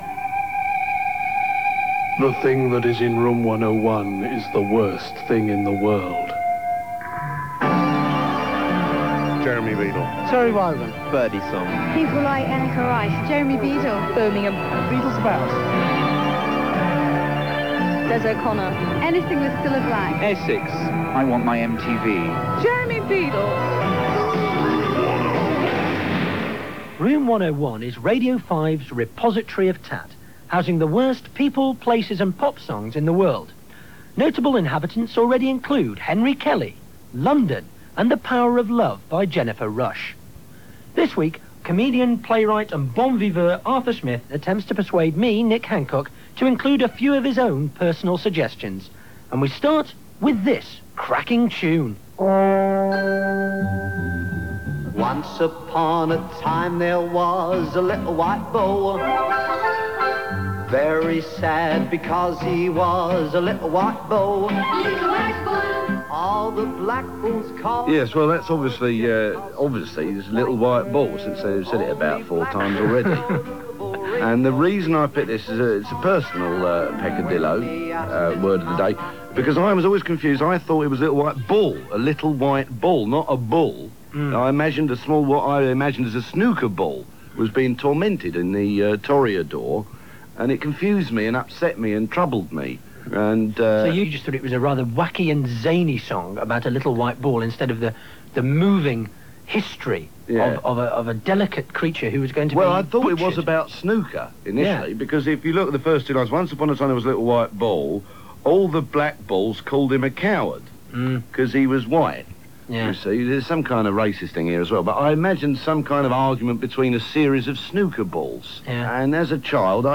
Arthur appears with Nick Hancock on the original Radio version of Room 101 – sadly this recording is (just) incomplete, but you can still hear him complain about ‘The Little White Bull’, ‘Kleptomania’ by his own band, Results!, ‘Puppet on a String’, yodelling and Michael Powell’s Long Jump.